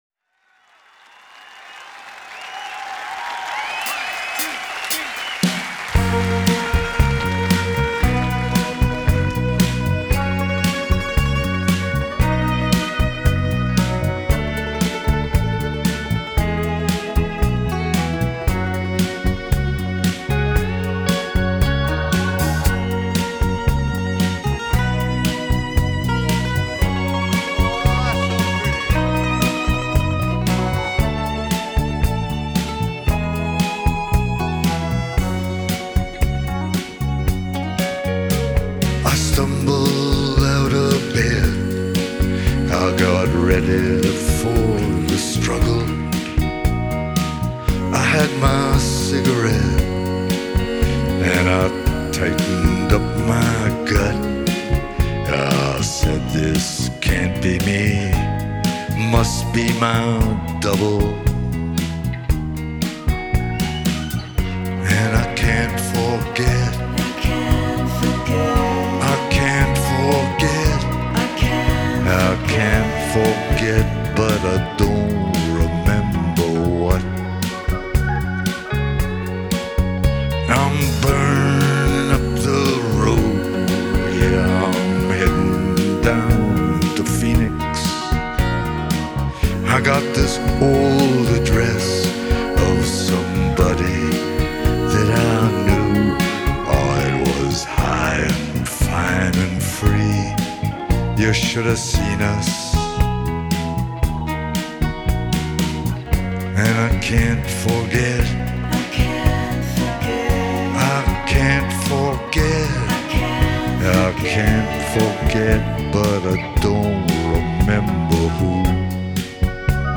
Genre: Folk, Blues, Singer-Songwriter
Live at Copenhagen Show, 2012